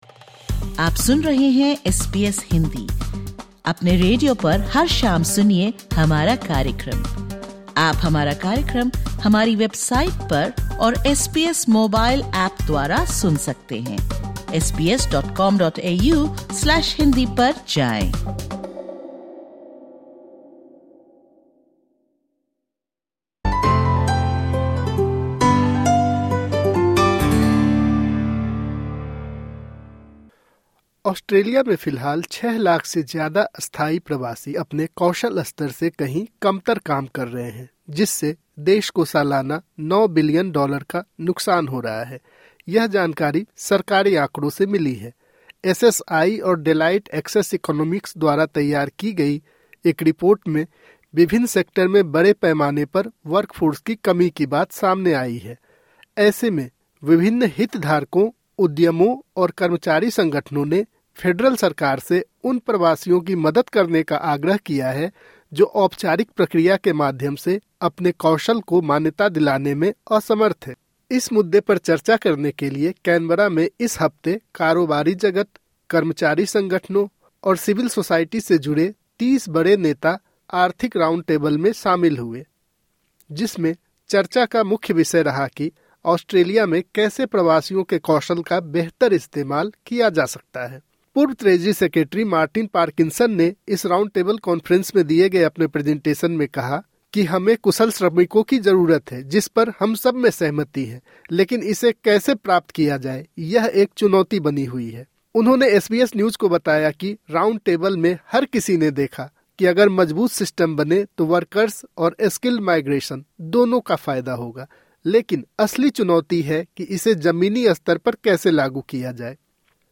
(Disclaimer: The information given in this interview is of a general nature.